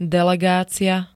delegácia [d-] -ie pl. G -ií D -iám L -iách ž.
Zvukové nahrávky niektorých slov